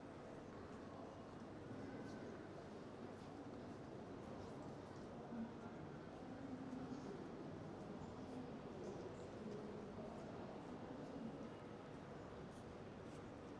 amb_vokzal.wav